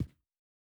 Shoe Step Stone Medium B.wav